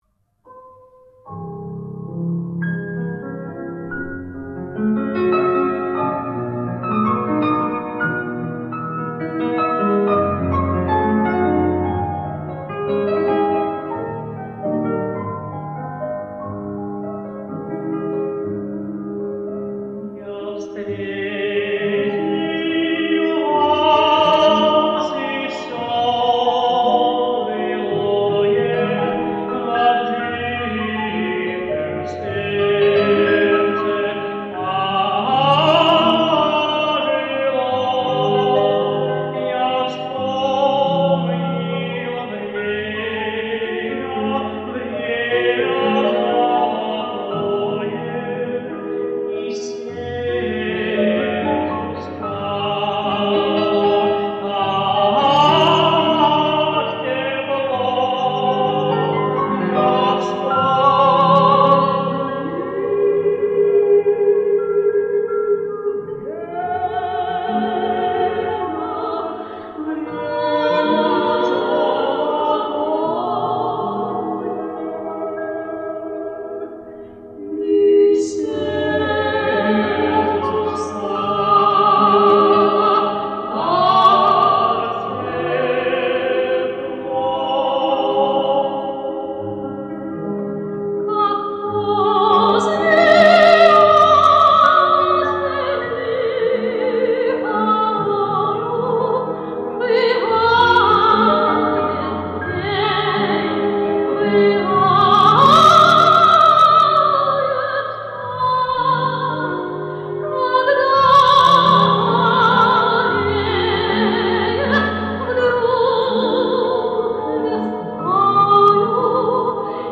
Романс в исполнении дуэта.